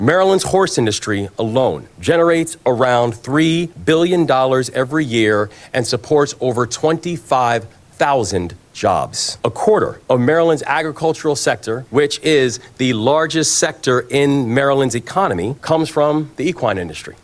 Preakness Festival 150 plans were rolled out at a Camden Yards press conference Wednesday afternoon.